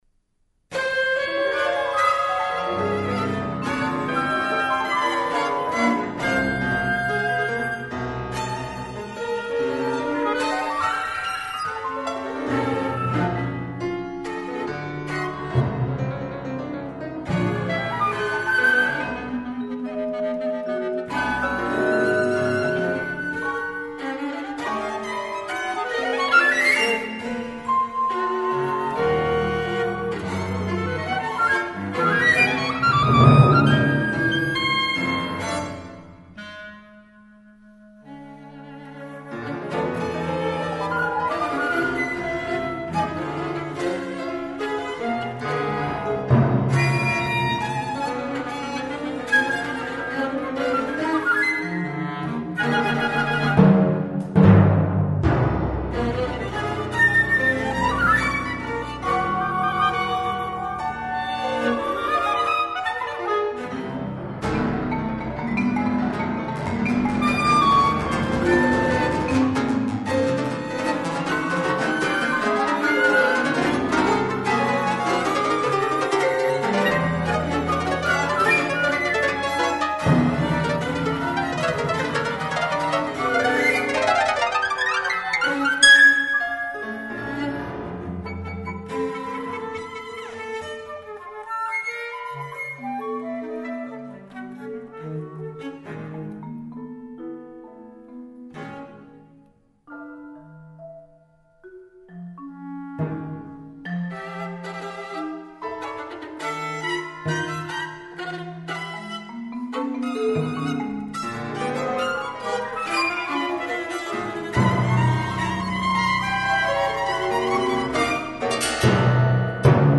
chamber work
for six instruments